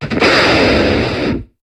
Cri de Crocorible dans Pokémon HOME.